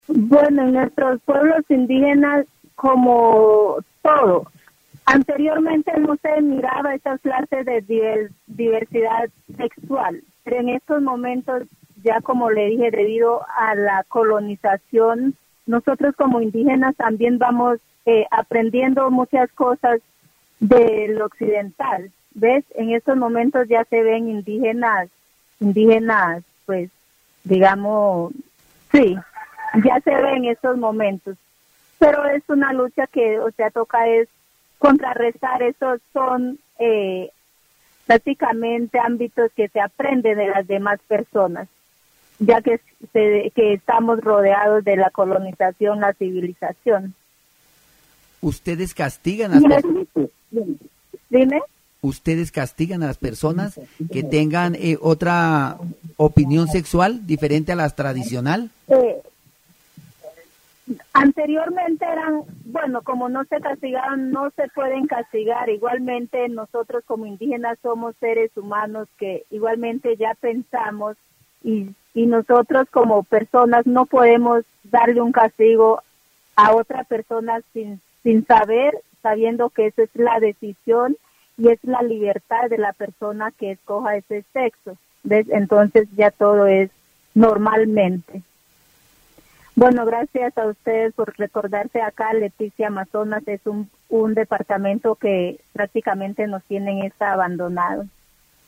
dc.descriptionEl programa radial aborda el tema de la diversidad sexual dentro de las comunidades indígenas del Amazonas, destacando cómo la colonización ha influido en su percepción y aceptación de la diversidad de género y orientación sexual. Un representante indígena explica que, en el pasado, no se visibilizaban estas diferencias en sus pueblos, pero actualmente hay una mayor apertura, aunque todavía es un tema de debate y resistencia.
dc.subject.lembProgramas de radio